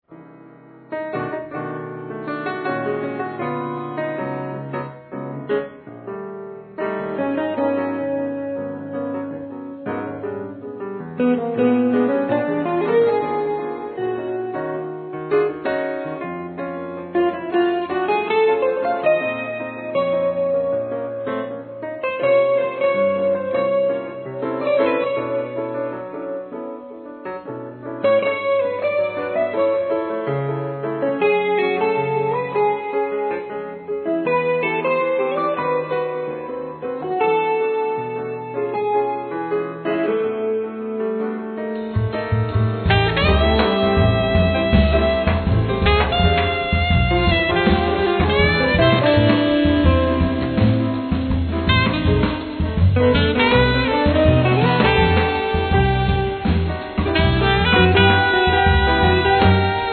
Piano,Keyboards
Alt & soprano sax
Guitars
Violin
Drums
Bass